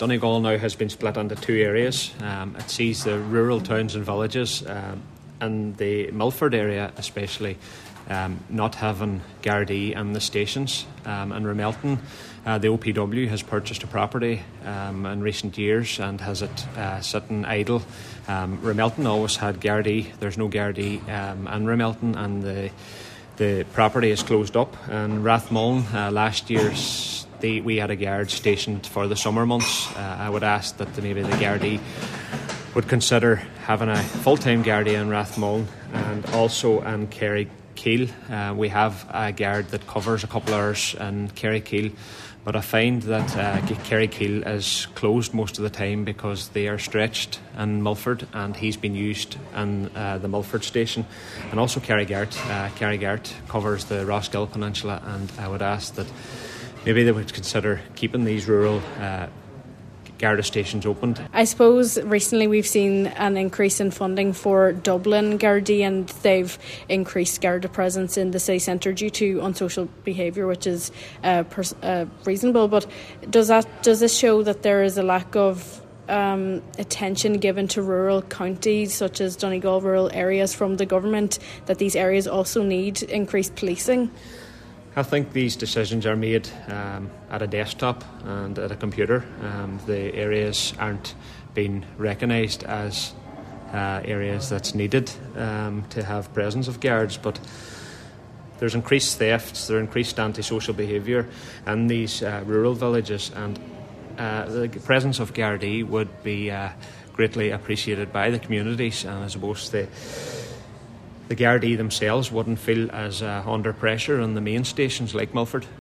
Councillor McGarvey says no town can be neglected and residents must be protected at all times: